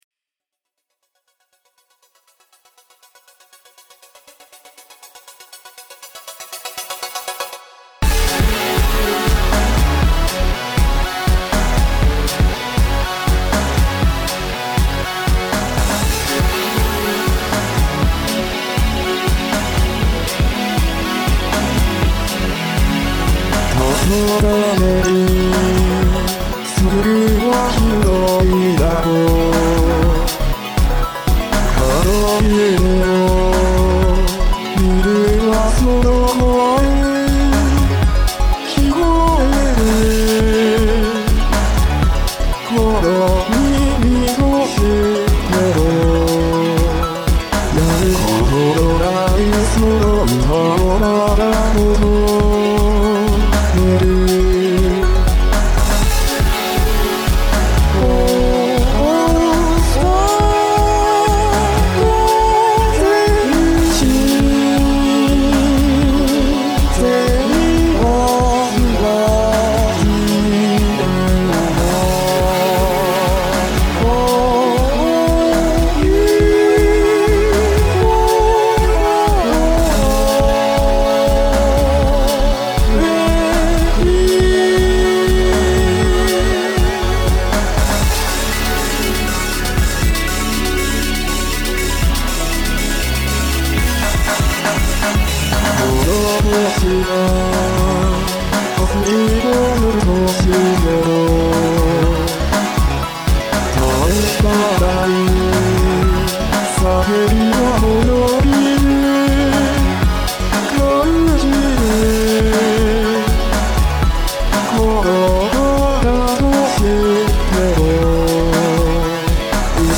サビ？からは↑音源使用です。
わからずとりあえず左右に振って重ねてみたら遠いところにいってしまいました。